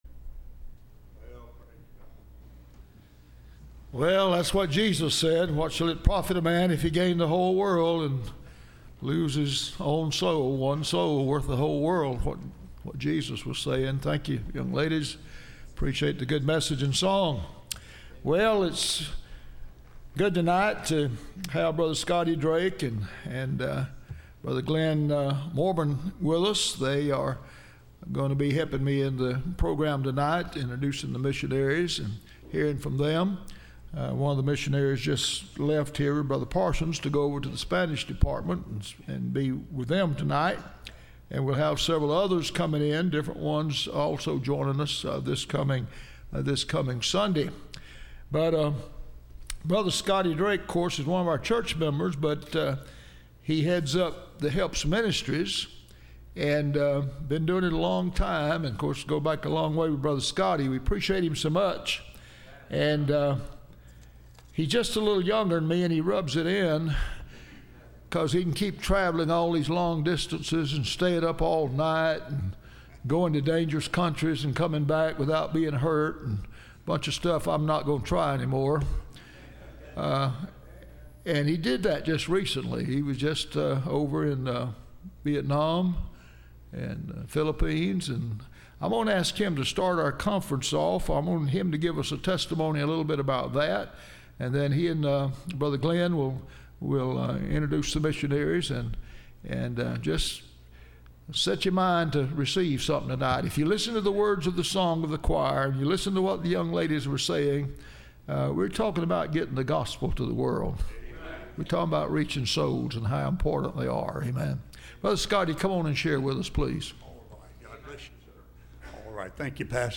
Service Type: Wednesday Missionary